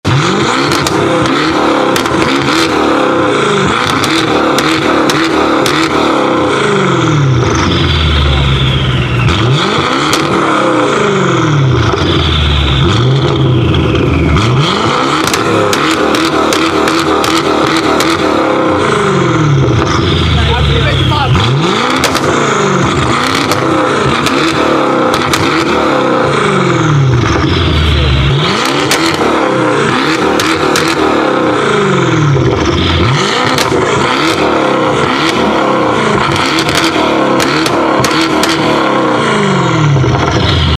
Звуки выхлопной системы